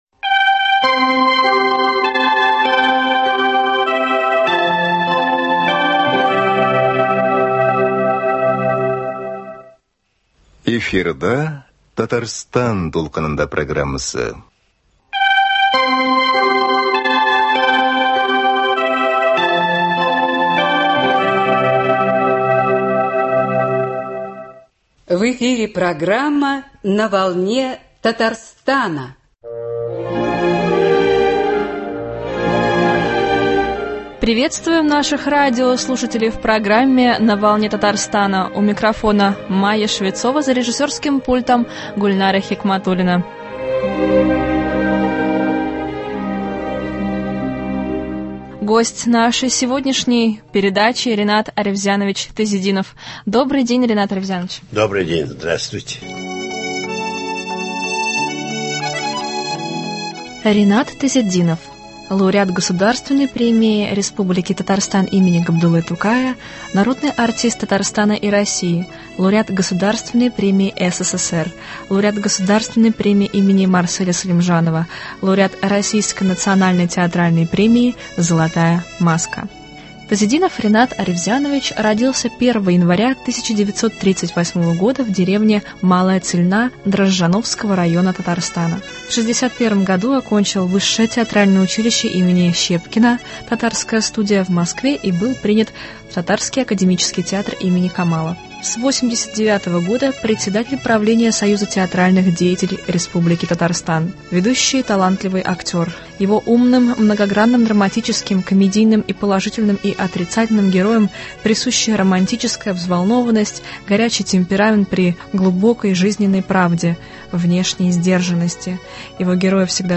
Народный артист РСФСР, народный артист Татарской АССР, лауреат государственной премии имени Габдуллы Тукая, лауреат премии «Золота маска» Ринат Тазетдинов о профессии артиста, об учебе в Щепкинском и том, как завоевать любовь зрителя рассказал в программе «На волне Татарстана». Передача подготовлена к 85-летию артиста.